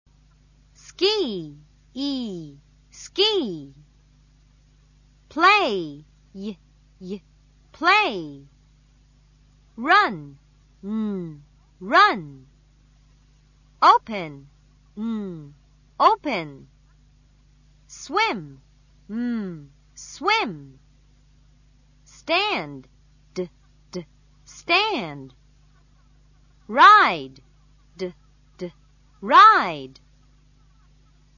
動詞の最後の音によって(e)sの発音の仕方がちがいます。
a.  語尾の発音がにごっている場合
発音がにごっているときは[z]と発音されます。